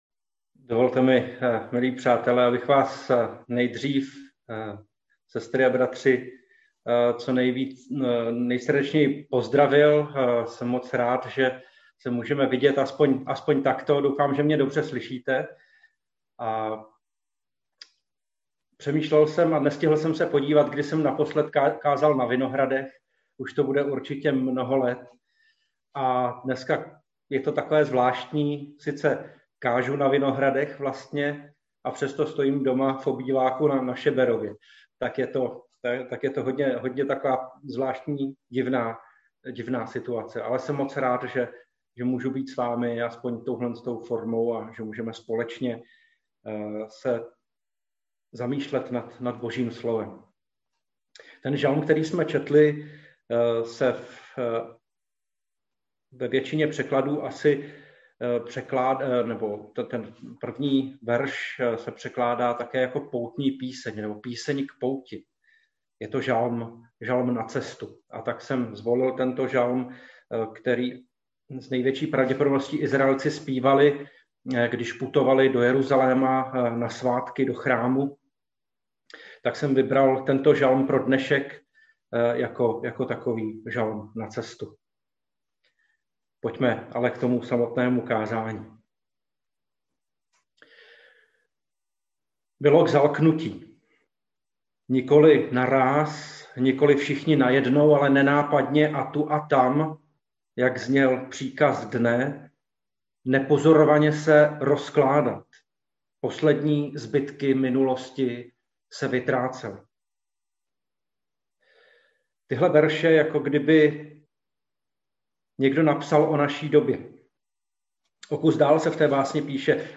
Nedělní kázání 31.1.2021